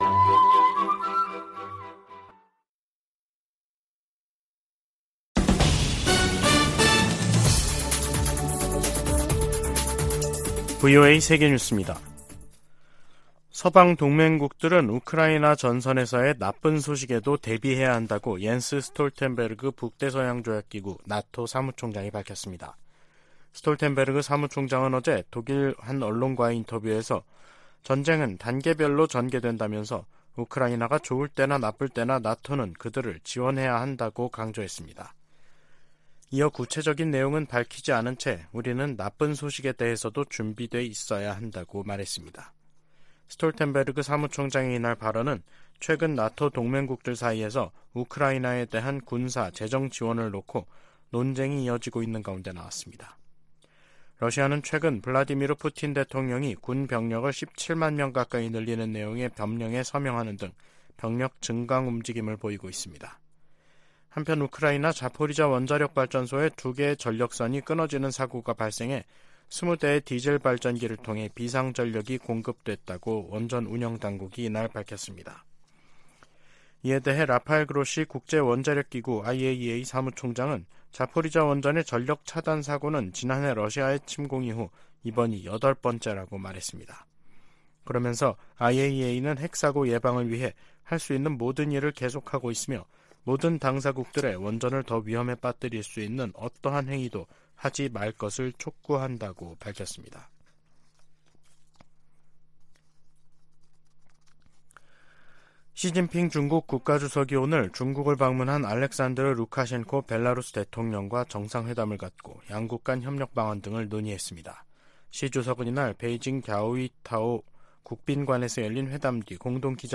VOA 한국어 간판 뉴스 프로그램 '뉴스 투데이', 2023년 12월 4일 2부 방송입니다. 북한에 이어 한국도 첫 군사정찰위성 발사에 성공하면서 남북한 간 위성 경쟁이 치열해질 전망입니다. 줄리 터너 미 국무부 북한인권특사가 1~5일 로스엔젤레스와 호놀룰루를 방문해 북한 인권 관계자 등을 만난다고 국무부가 밝혔습니다. 미국과 한국이 '제3차 민주주의∙거버넌스 협의체' 회의를 개최하고 인권과 민주주의 증진 방안을 논의했습니다.